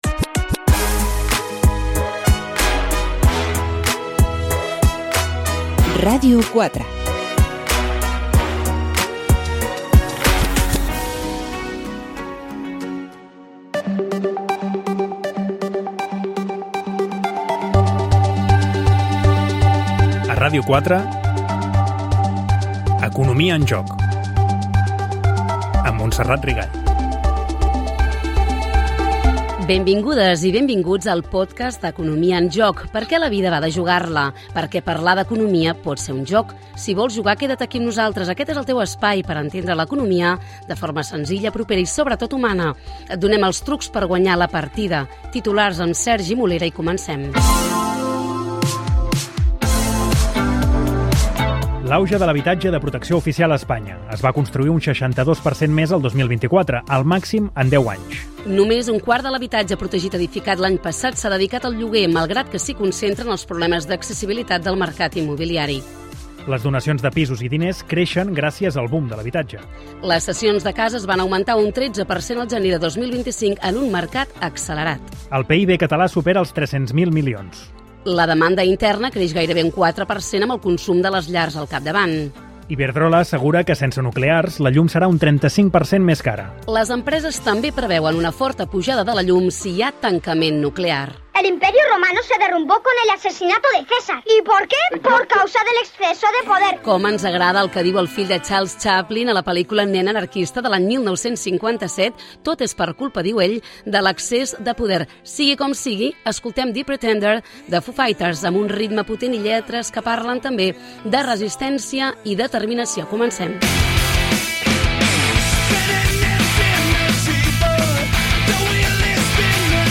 Indicatiu de l'emissora, careta del programa, presentació del primer programa, titulars, tema musical, debat sobre l'Economia del Bé Comú Gènere radiofònic Divulgació